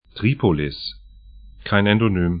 Tripolis 'tri:polɪs Ţarābulus al Gharb ar Stadt / town 32°54'N, 13°11'E